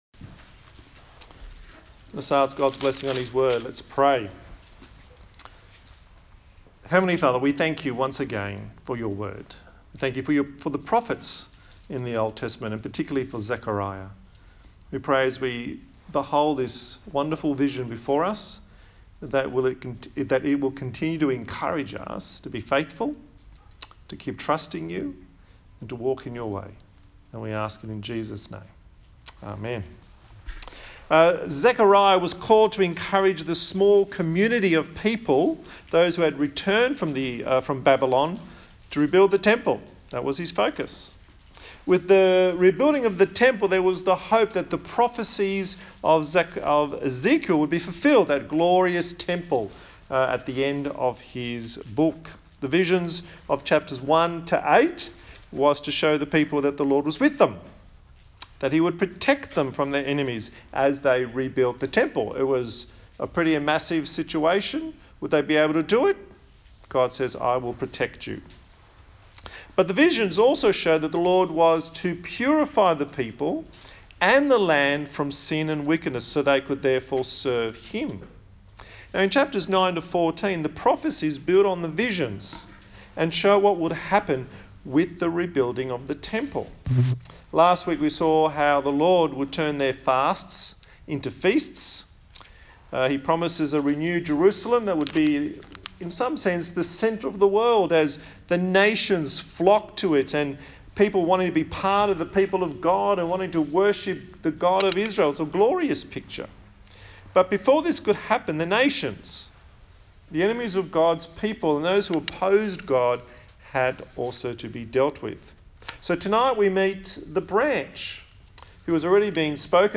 Zechariah Passage: Zechariah 9:1-11:3 Service Type: TPC@5